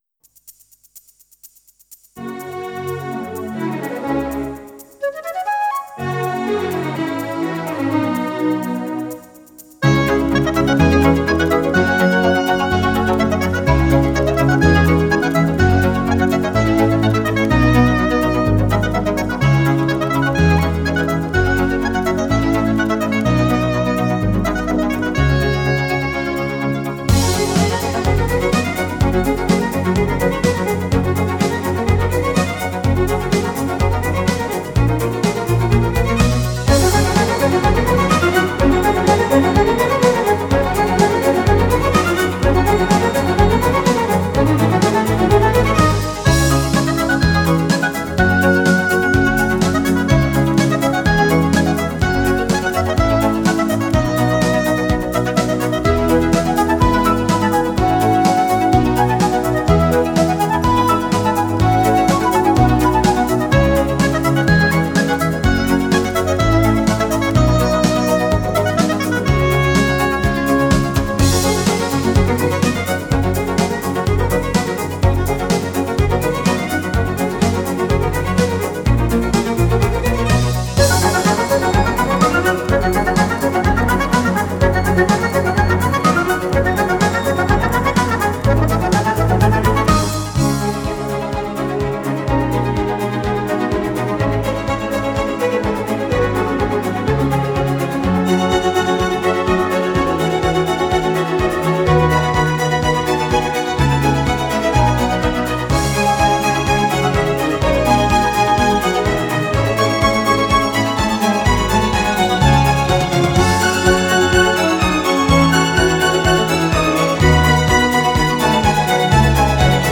Genre: Classical / Neo Classical